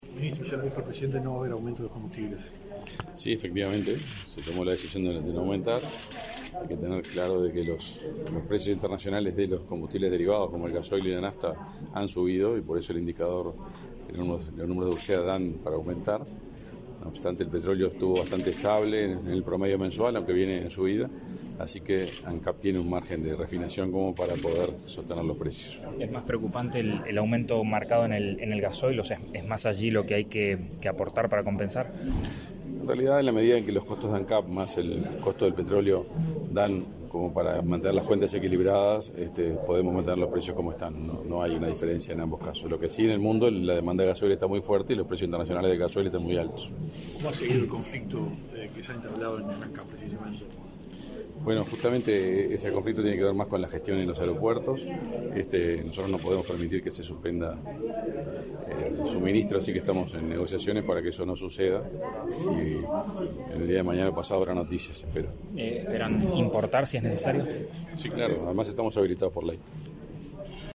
Declaraciones a la prensa del ministro de Industria, Energía y Minería, Omar Paganini
Declaraciones a la prensa del ministro de Industria, Energía y Minería, Omar Paganini 31/10/2022 Compartir Facebook X Copiar enlace WhatsApp LinkedIn El ministro de Industria, Energía y Minería, Omar Paganini, integró la delegación que, encabezada por el presidente de la República, Luis Lacalle Pou, visitó Japón. Tras regresar al país, realizó declaraciones a la prensa.